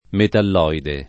metalloide